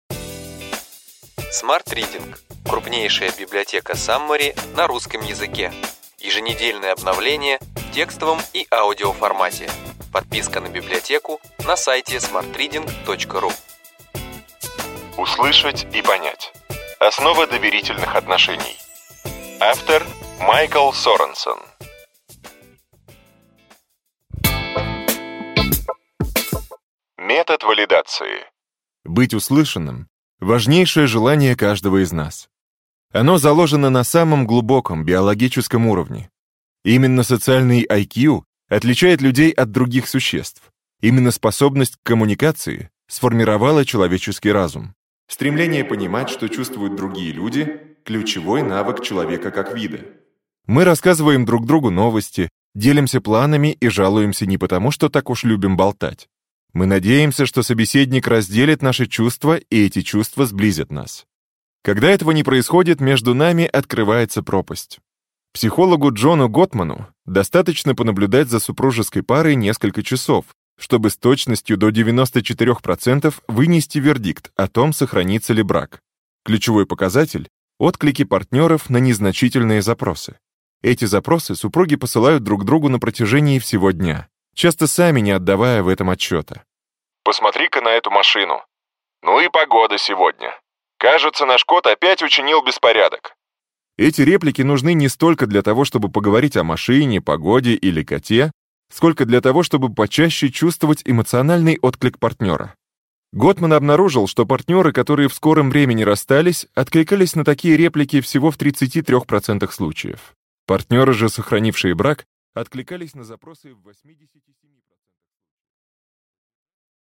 Аудиокнига Ключевые идеи книги: Услышать и понять. Основа доверительных отношений.